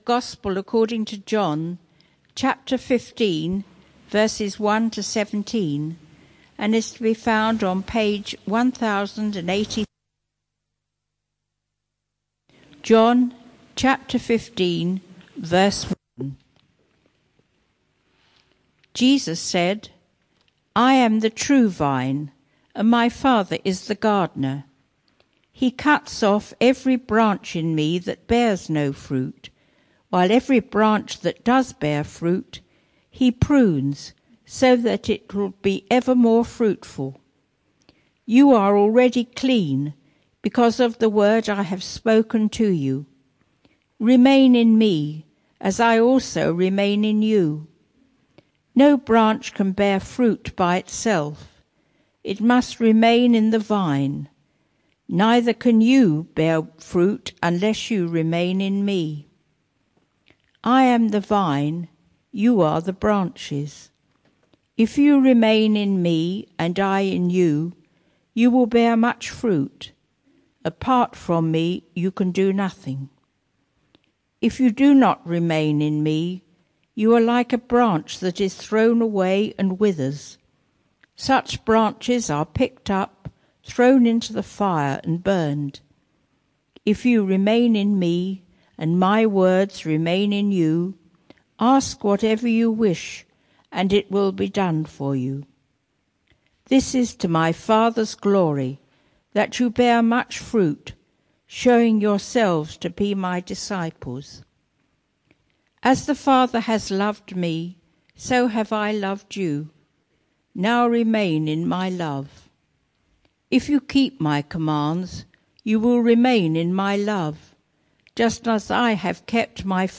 This sermon is part of a series: 22 May 2016